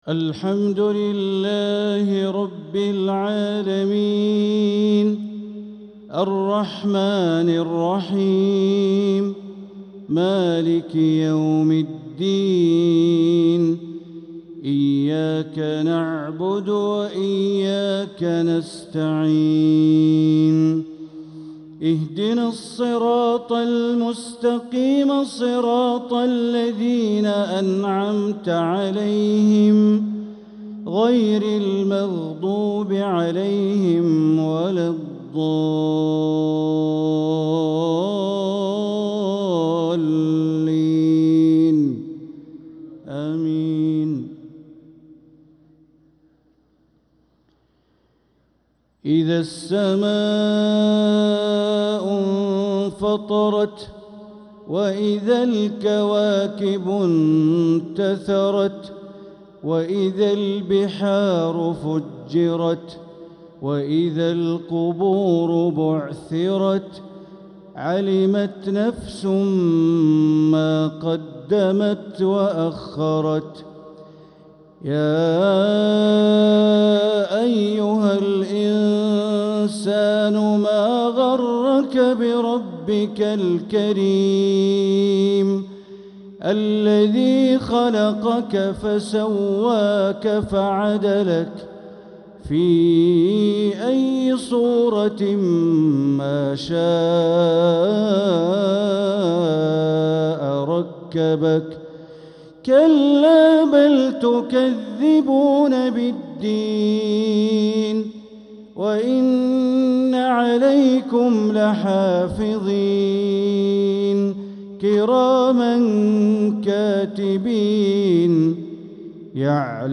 isha prayer Surat al-Infitar 4-2-2025 > 1446 > Prayers - Bandar Baleela Recitations